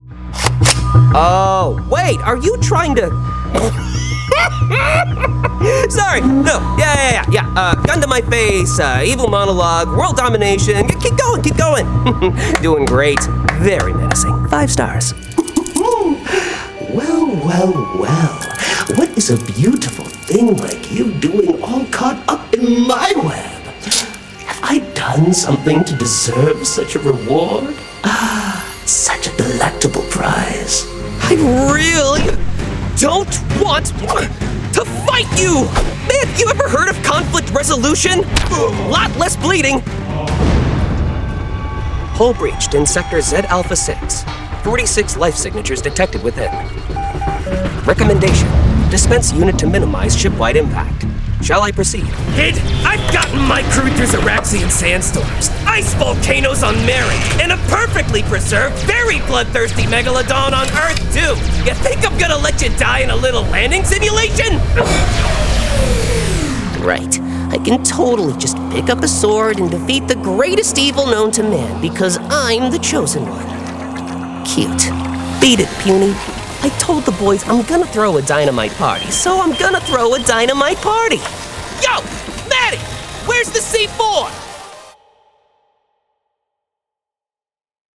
Demo
Teenager, Young Adult, Adult
Has Own Studio
That millennial/GenZ sound works wonders when marketing to youngsters.
standard us | natural
ANIMATION 🎬
COMMERCIAL 💸
GAMING 🎮
NARRATION 😎
warm/friendly